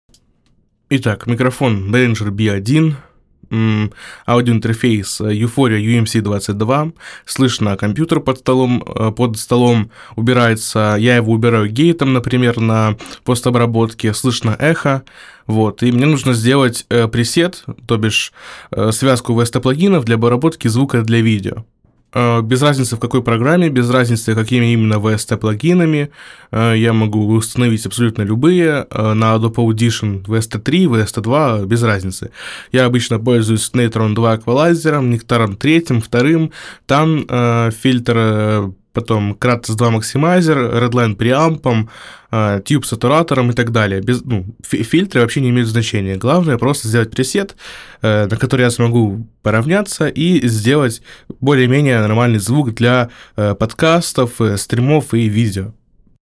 Здравствуйте, купил себе Behringer B-1, работает он у меня в связке с U-Phoria UMC22.